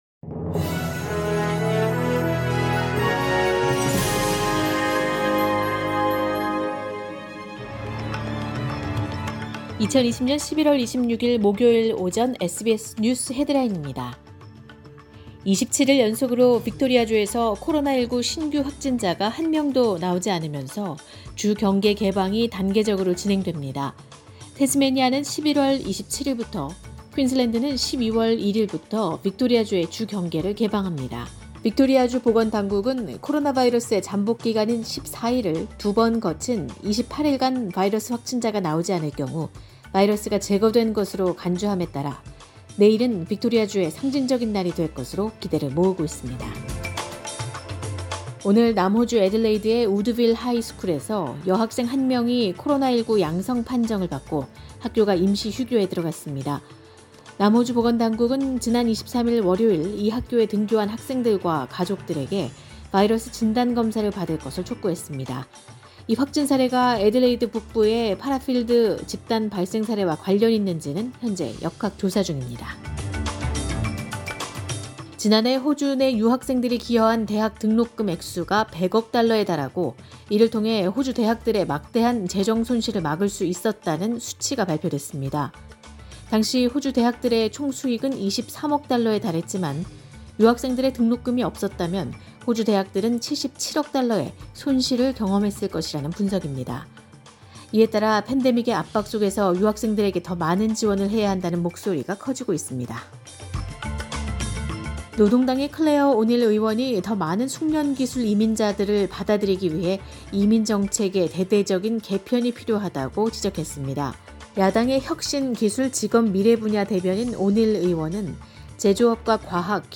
2020년 11월 26일 목요일 오전의 SBS 뉴스 헤드라인입니다.